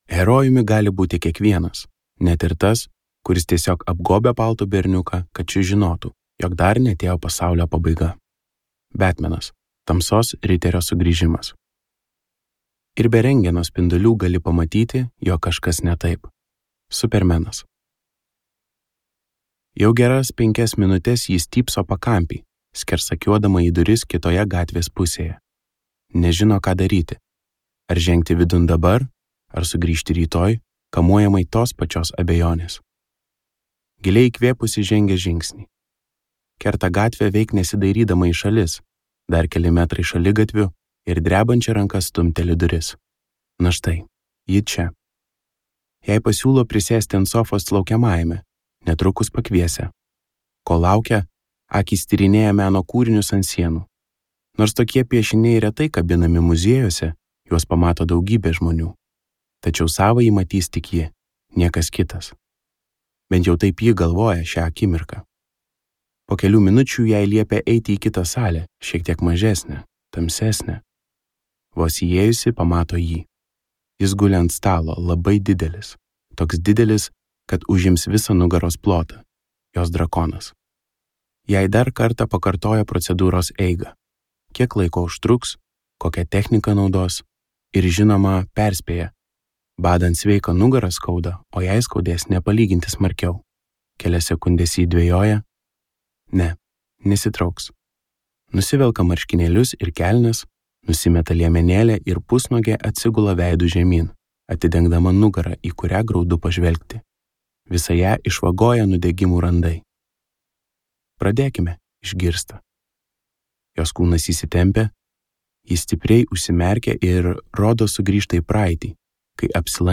Nematomas | Audioknygos | baltos lankos